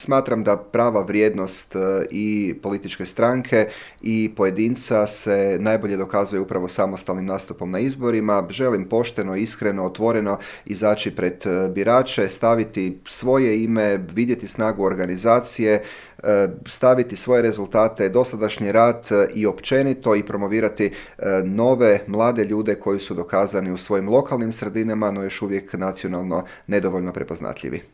ZAGREB - Međimurski župan Matija Posavec, nositelj HNS-ove liste za europske izbore, u razgovoru za Media servis otkrio je zašto ustraje na samostalnom izlasku, predstavlja li HNS-u vodstvo uteg, planira li zasjesti na mjesto predsjednika stranke i zašto nije bio za ulazak u vladajuću koaliciju.